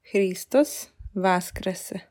Hristos vaskrese! (tap for pronunciation) Or: